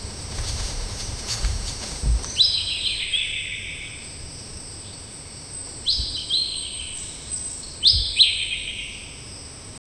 A sense of humor is added by a light cartoon accent that makes the sound bright and recognizable 0:01 Título: Escena 2 – Selva (20 s) Descripción: Selva al amanecer con aves, viento y hojas. León dormido, pasos rápidos de ratón, tropezón, rugido corto, chillido del ratón y risa leve del león. Natural, sin música. 0:10
ttulo-escena-2--selva-jto5lfpl.wav